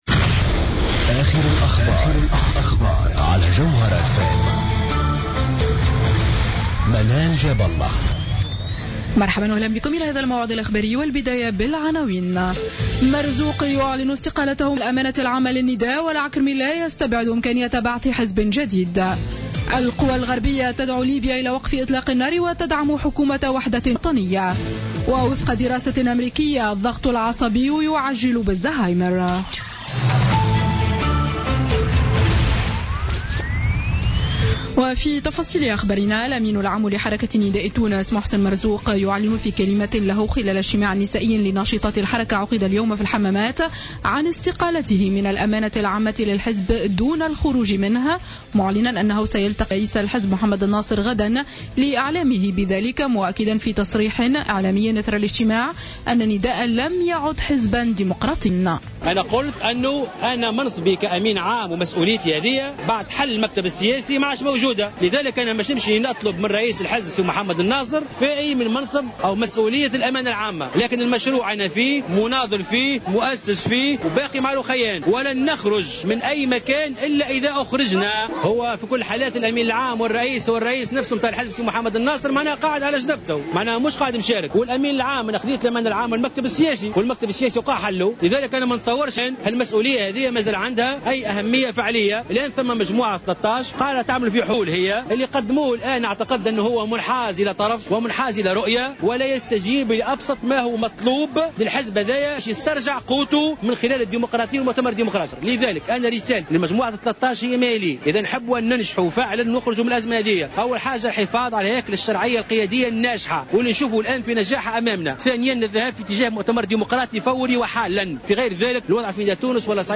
نشرة أخبار السابعة مساء ليوم الأحد 13 ديسمبر 2015